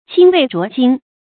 清渭濁涇 注音： ㄑㄧㄥ ㄨㄟˋ ㄓㄨㄛˊ ㄐㄧㄥ 讀音讀法： 意思解釋： 《詩·邶風·谷風》：「涇以渭濁，湜湜其沚。」